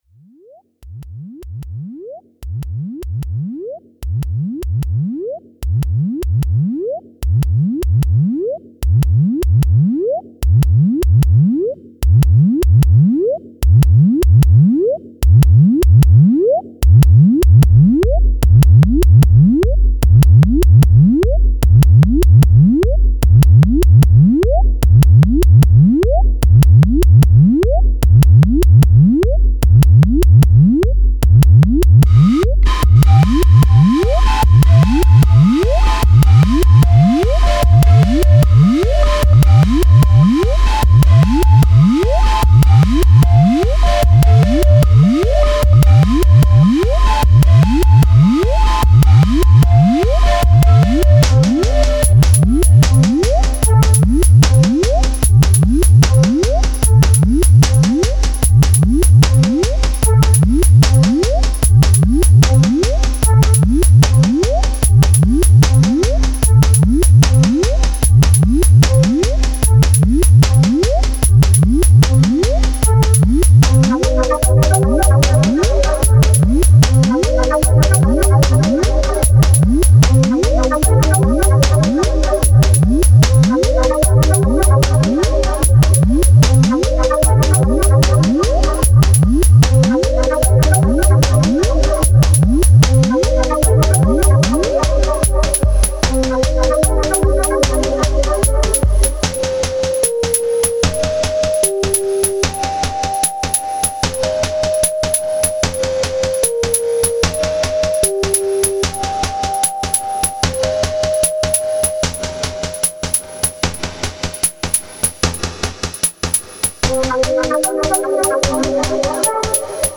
Genre Freestyle